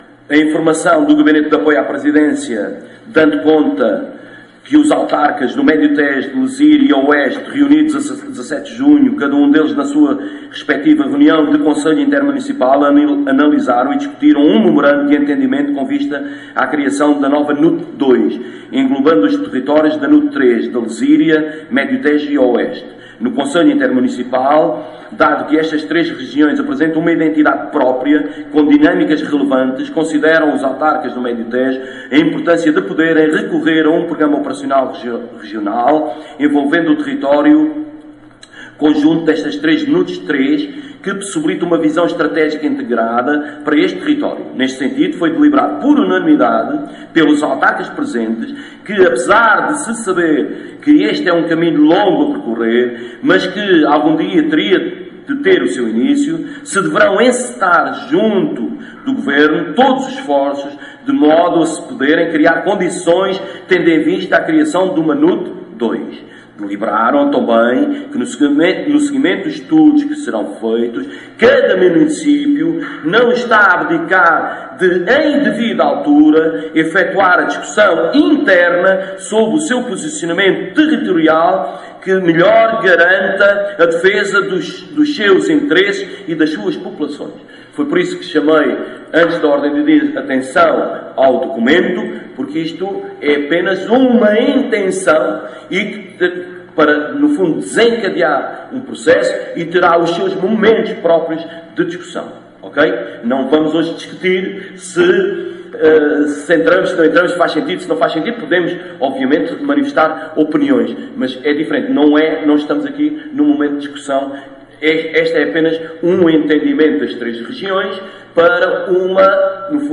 Na última reunião de executivo, dia 30 de junho, o presidente da Câmara Municipal de Abrantes deu conta que os presidentes das Comunidades Intermunicipais do Médio Tejo, Lezíria e Oeste, reunidos no dia 17 de junho em Santarém, assinaram um memorando de entendimento com vista à criação de uma nova NUT II, englobando os territórios das NUTS III da Lezíria, Médio Tejo e Oeste.
ÁUDIO | PRESIDENTE DA CÂMARA, MANUEL JORGE VALAMATOS